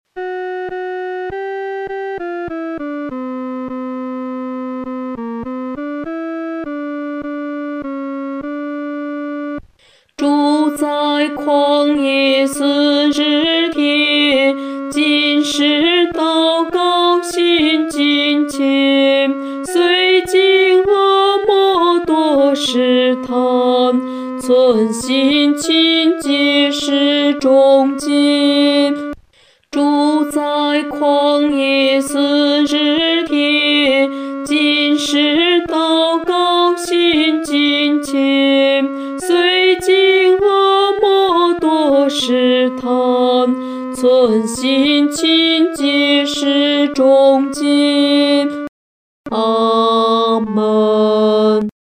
合唱
女低
本首圣诗由网上圣诗班 (石家庄一组）录制
这首诗歌宜用不太慢的中速弹唱。